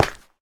Minecraft Version Minecraft Version 25w18a Latest Release | Latest Snapshot 25w18a / assets / minecraft / sounds / block / froglight / step3.ogg Compare With Compare With Latest Release | Latest Snapshot
step3.ogg